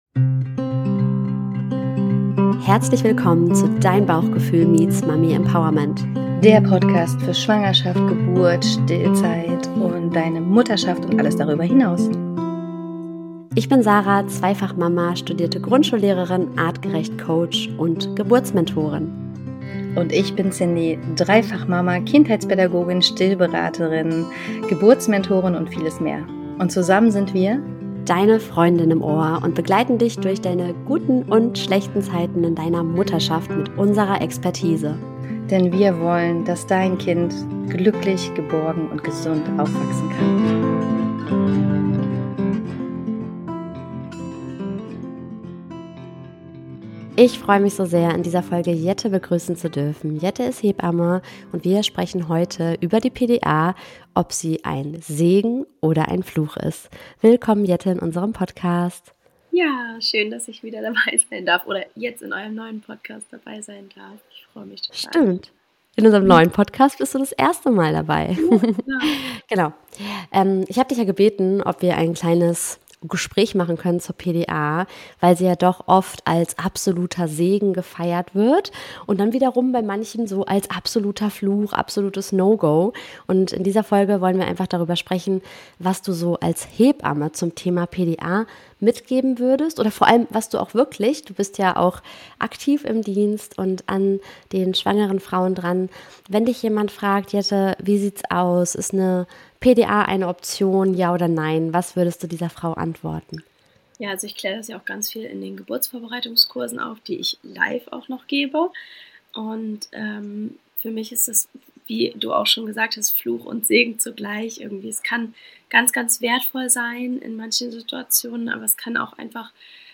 Gemeinsam diskutieren die beiden, warum Vorbereitung auf körperlicher und mentaler Ebene entscheidend ist und wie Frauen eine selbstbestimmte Geburt erleben können, ohne sich auf die PDA als Lösung verlassen zu müssen.